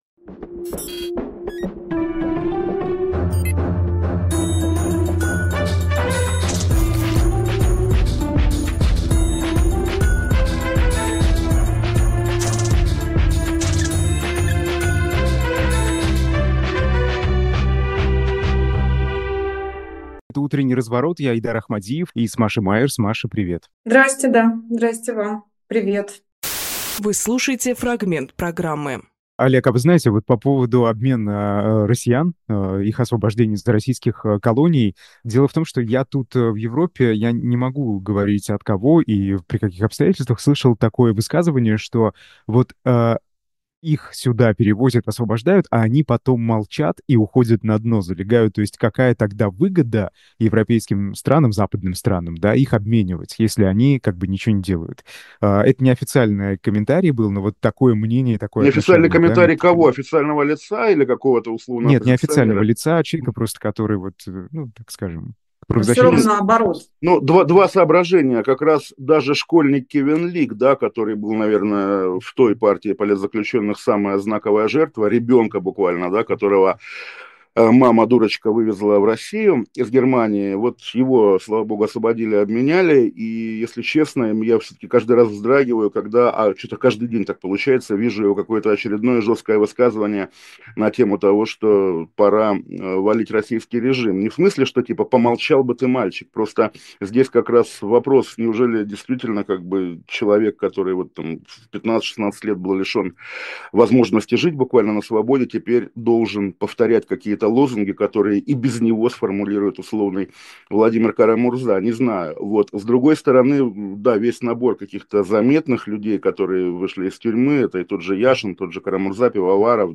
Олег Кашинжурналист
Фрагмент эфира от 21.11.24